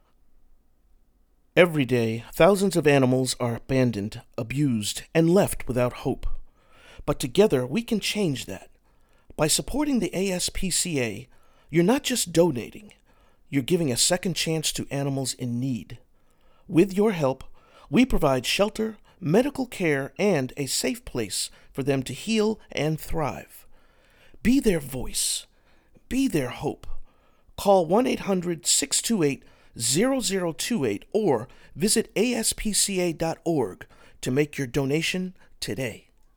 Male
Adult (30-50)
Dynamic voice with versatile range that brings various genres to life.
Narration
Words that describe my voice are conversational, neutral tone, Middle-aged.